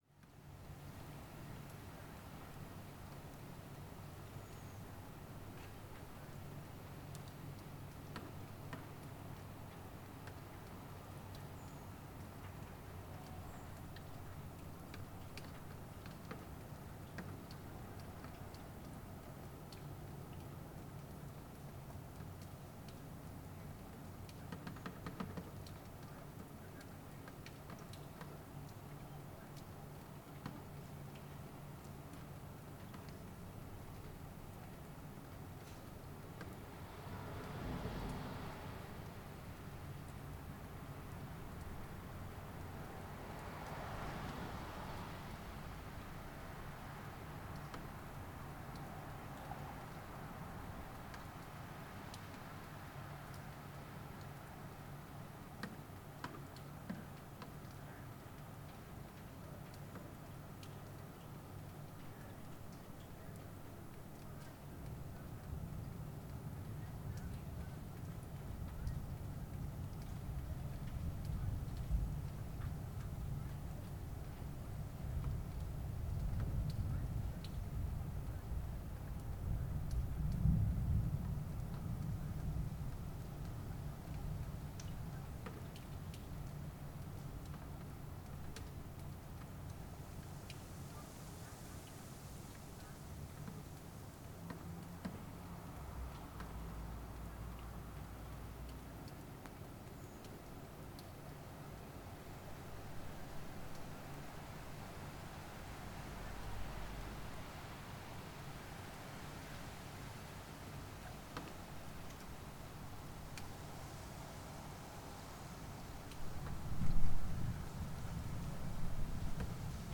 October night thunderstorm Sound Effect — Free Download | Funny Sound Effects
A brief, fairly rapidly moving thunderstorm with really torrential rain (real pandemonium) —